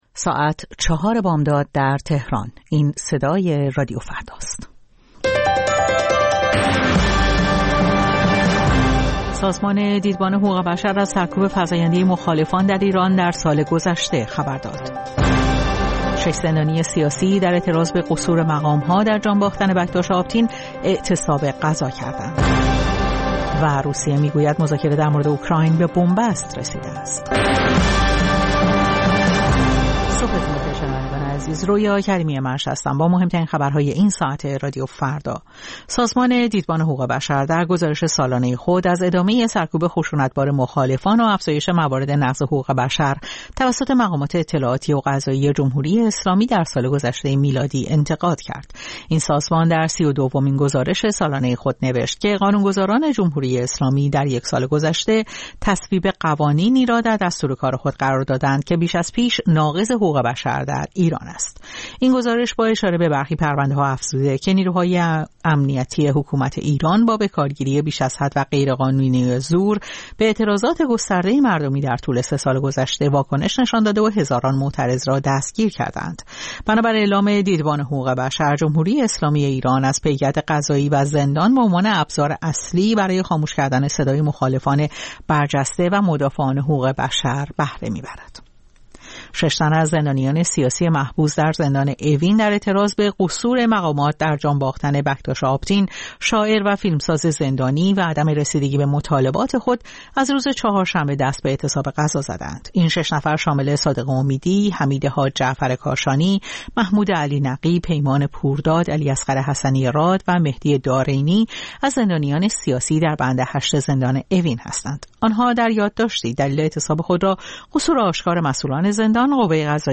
سرخط خبرها ۴:۰۰